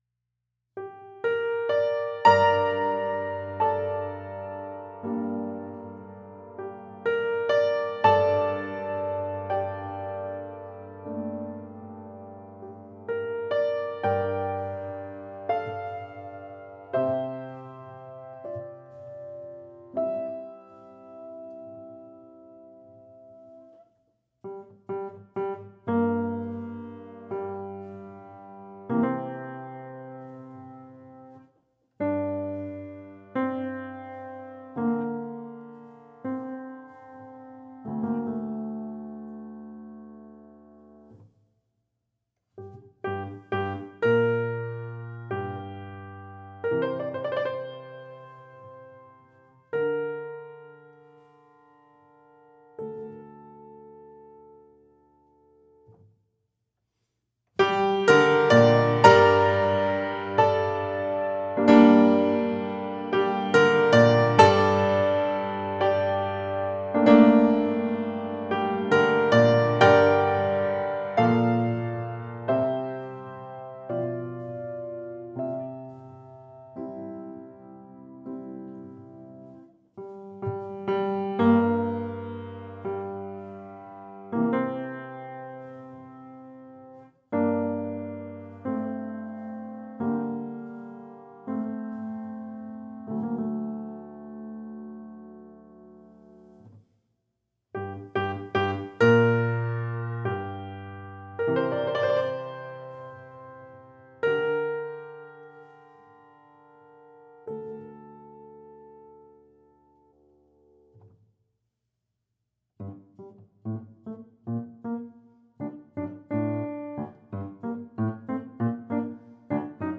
Piano, Música pedagogica